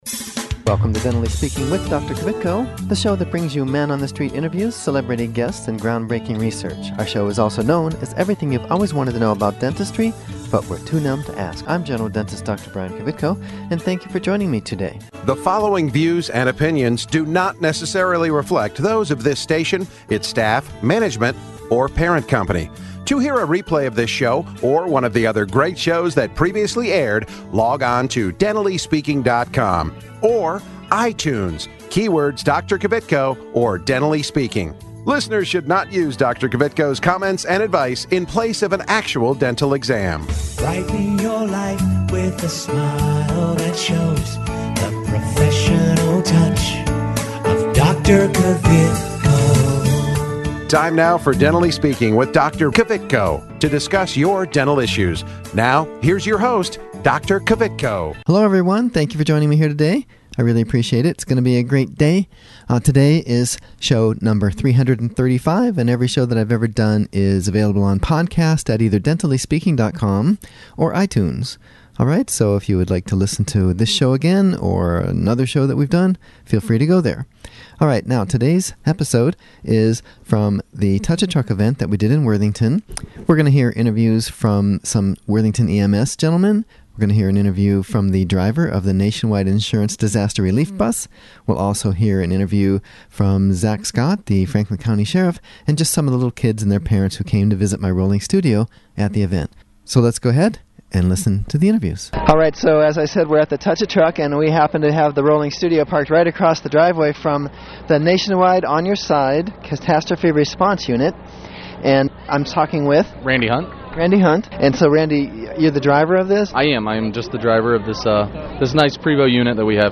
interviews from the Worthington Touch A Truck event. Hear from children in attendance, Franklin County Sheriff Zack Scott, The Worthington EMS, and The Nationwide Insurance Disaster Relief bus.